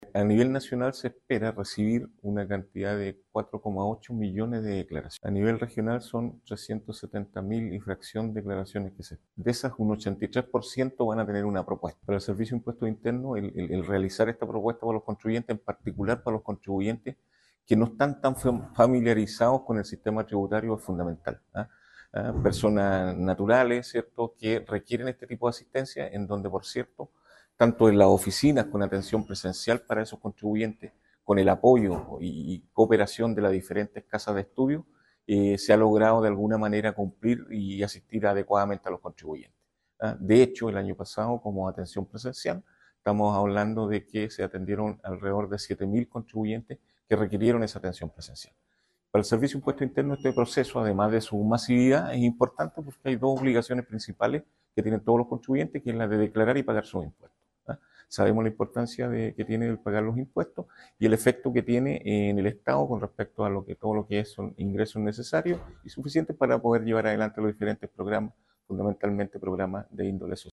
El director Regional del SII, Luis Hermosilla Mansilla, instó a los contribuyentes a revisar cuidadosamente la información antes de enviarla.